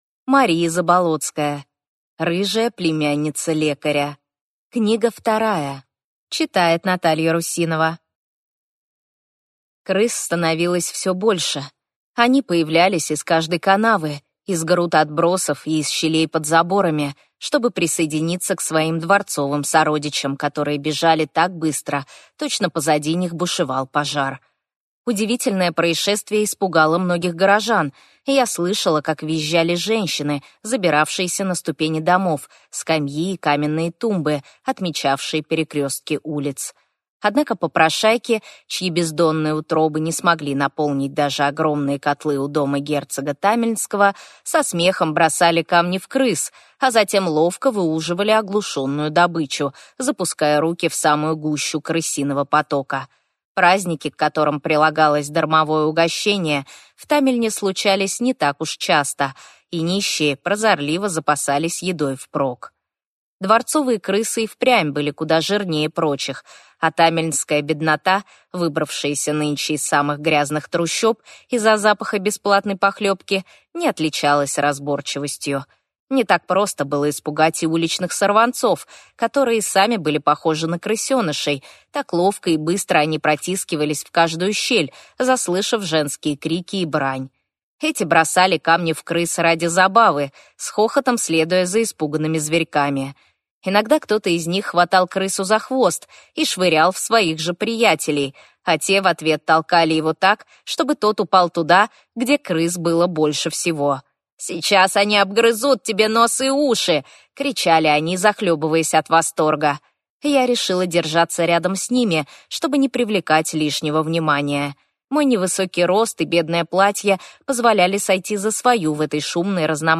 Аудиокнига Рыжая племянница лекаря-2